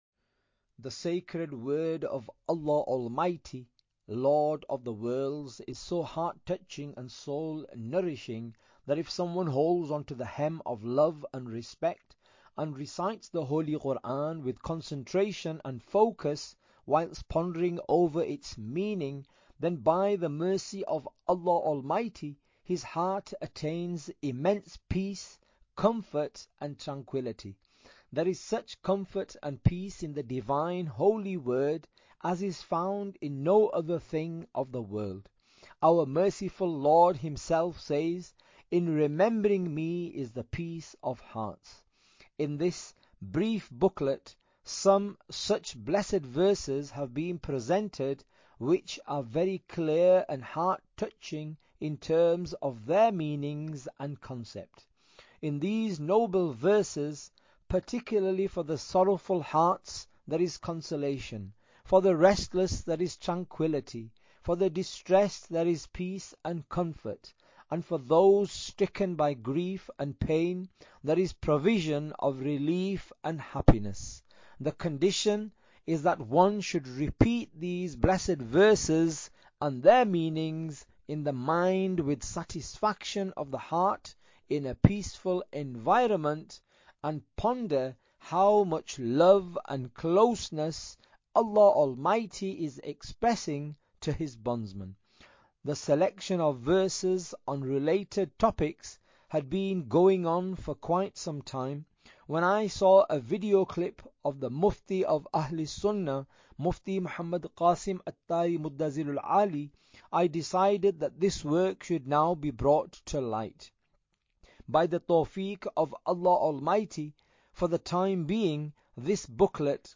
Audiobook - Peace Giving Quranic Verses (English)